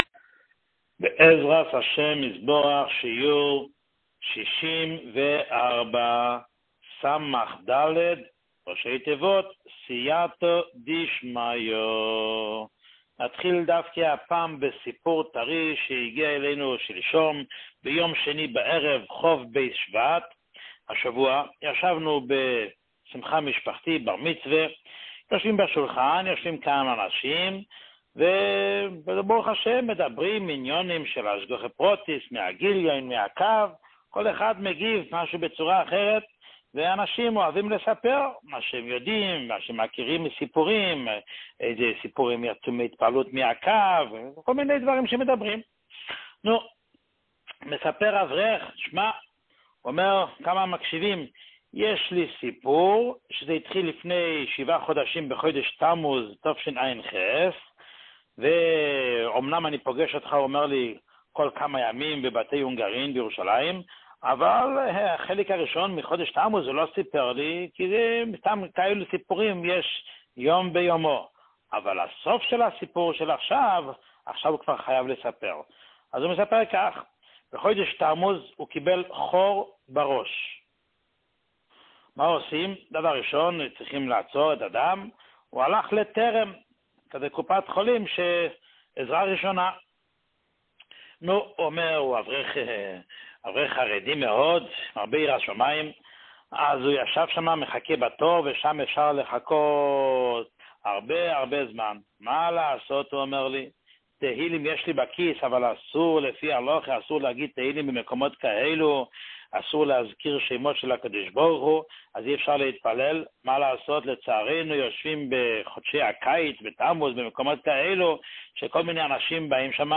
שיעורים מיוחדים
שיעור 64